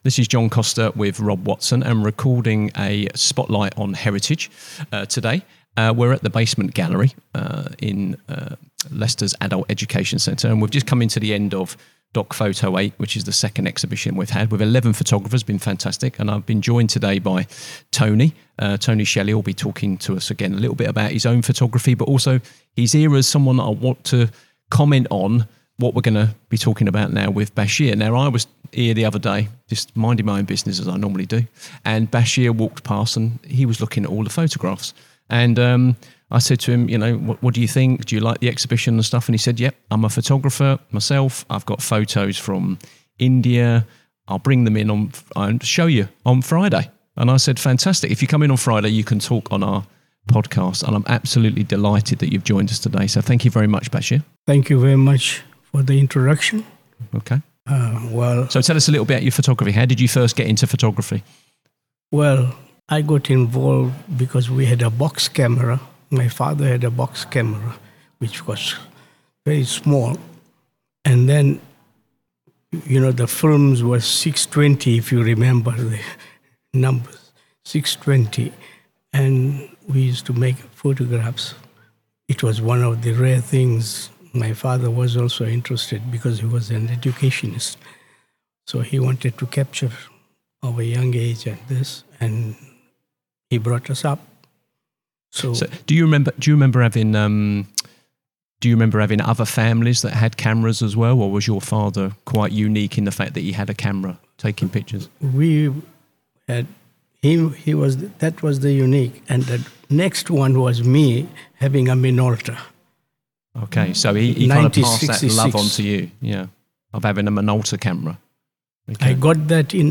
Preserving Memory Through Photography – A Conversation on Heritage and Identity – Soar Sound
Spotlight on Heritage returns to the Basement Gallery at Leicester’s Adult Education Centre during the closing days of the DocPhoto 8 exhibition.